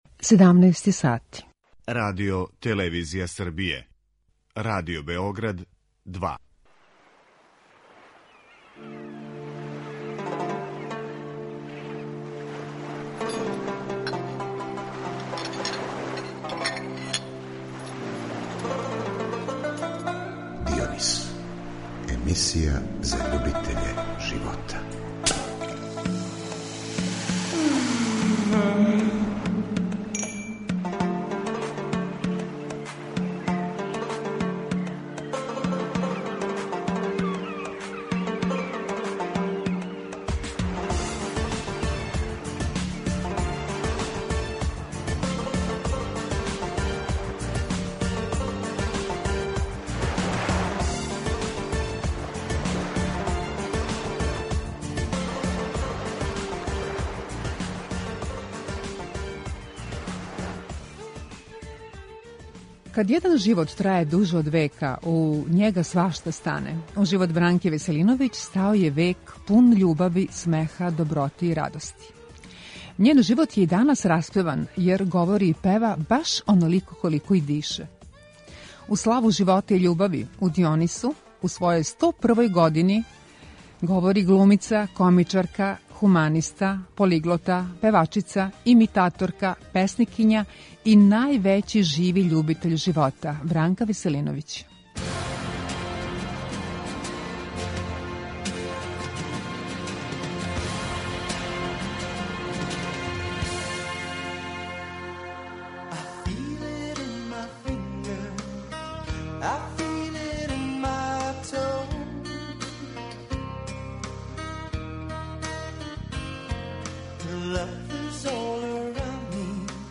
У славу живота и љубави, у својој сто првој години овако је у Дионису говорила комичарка, хуманиста, полиглота, певачица, имитаторка, песникиња и највећи љубитељ живота Бранка Веселиновић.